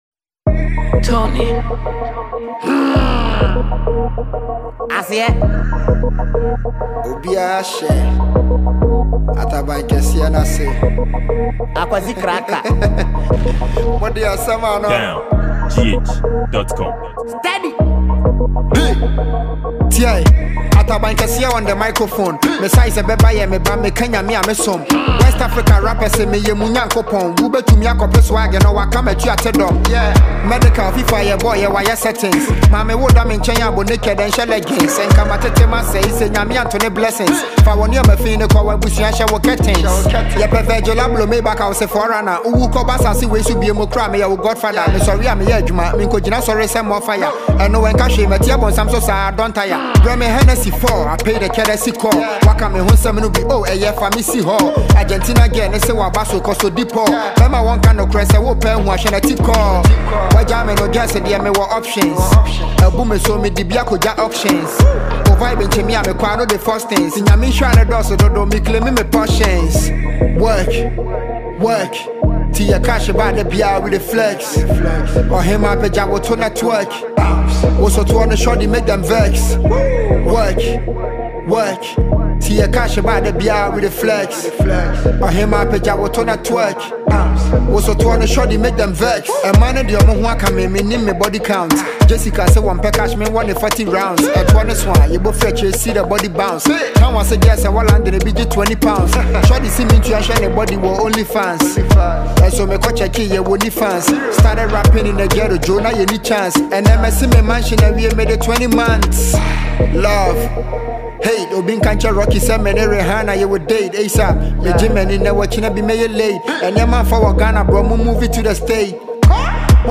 Ghana Music
a Ghanaian rapper and songwriter
captivating hot banger